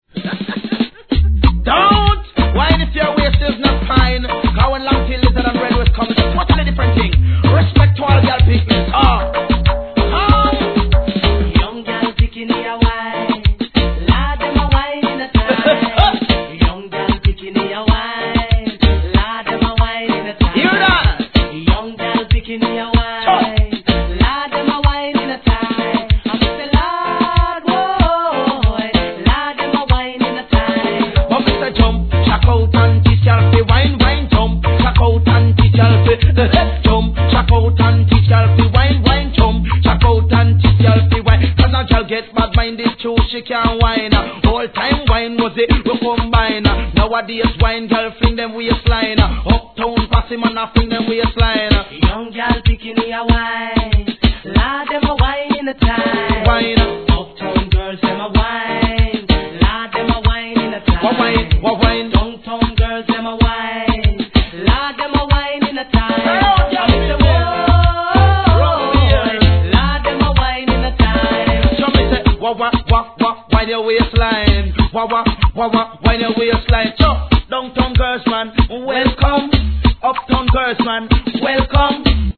REGGAE
1989年の大ヒットワイニ〜アンセム・コンビネーション！